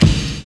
N.Y RAP    1.wav